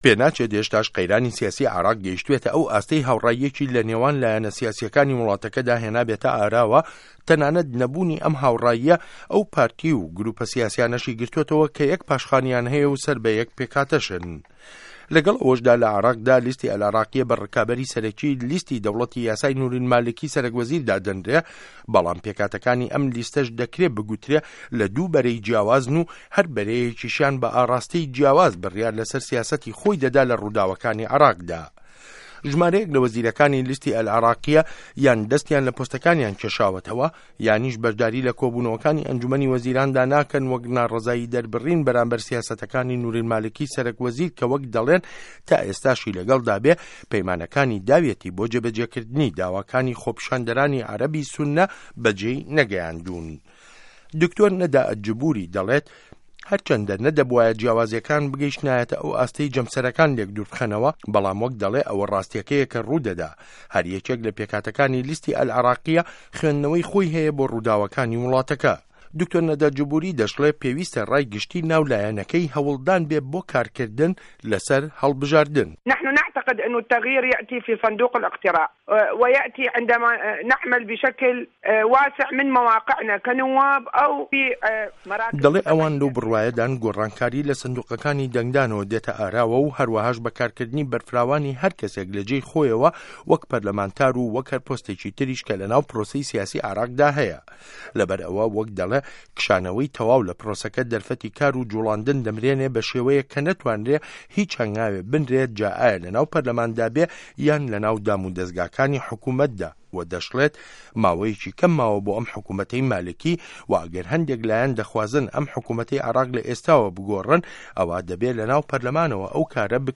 ڕاپۆرت له‌ سه‌ر بنچینه‌ی لێدوانه‌کانی دکتۆر نه‌دا ئه‌لجبوری